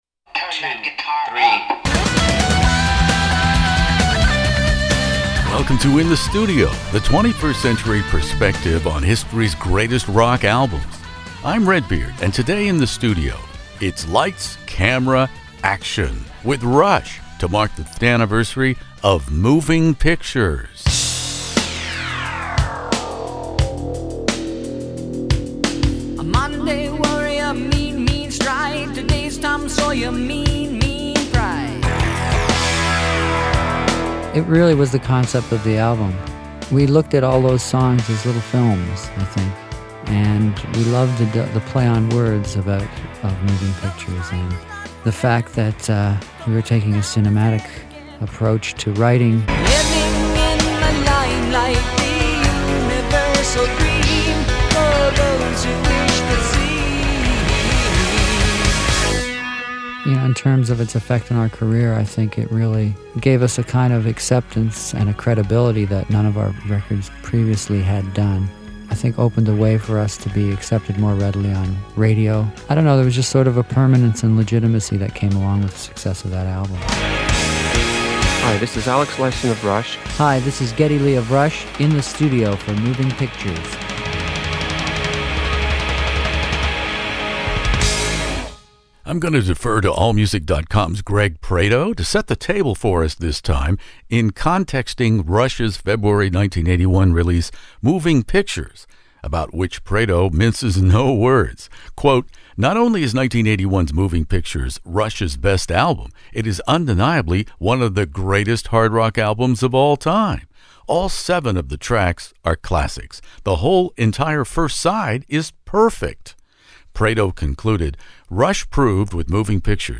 Rush Moving Pictures interview In the Studio Geddy Lee Alex Lifeson